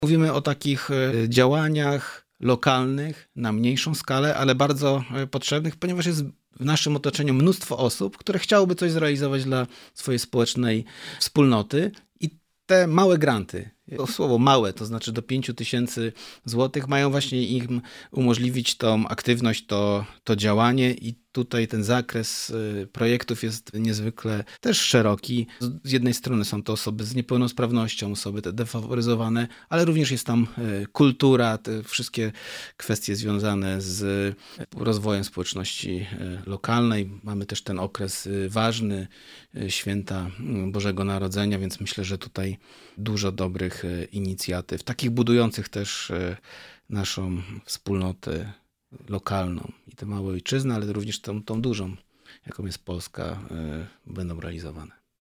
O ważny projekt przeznaczony dla lokalnej społeczności, zaznacza wicemarszałek Marcin Krzyżanowski.